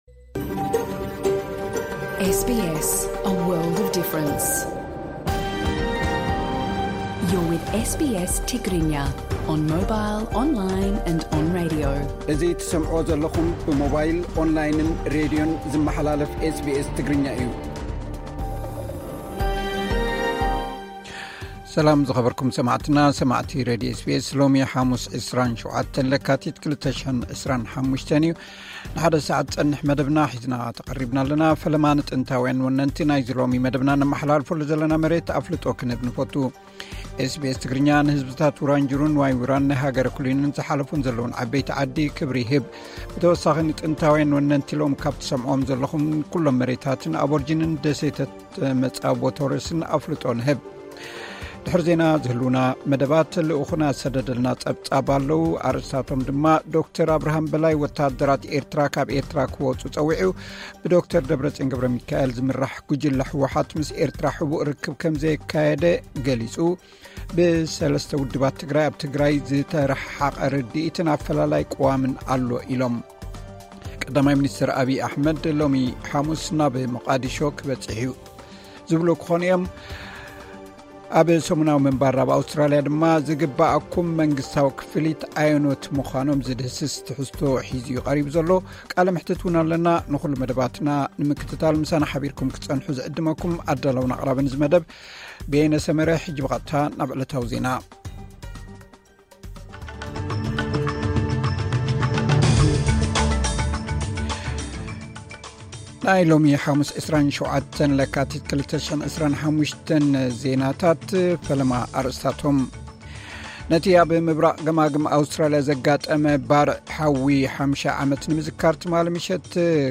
ዕለታዊ ዜና ኤስ ቢ ኤስ ትግርኛ (27 ለካቲት 2025)